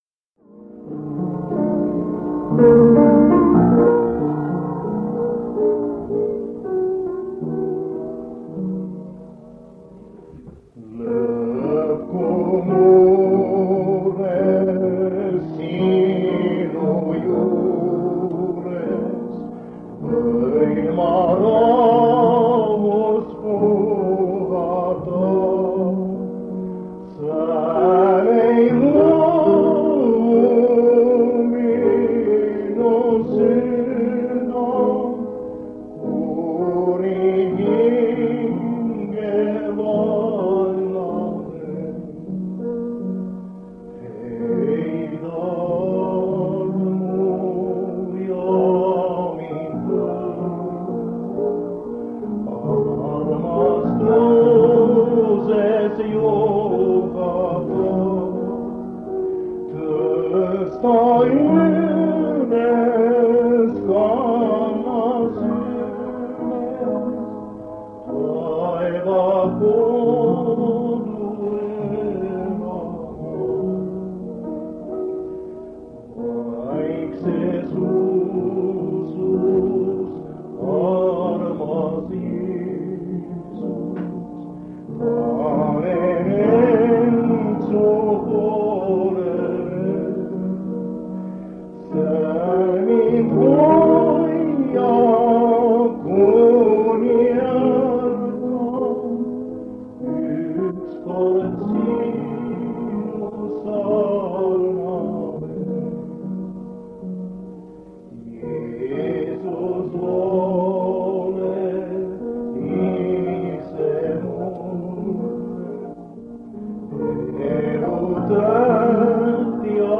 On aasta 1977 ja Paides toimub Evangeeliumi-nädal.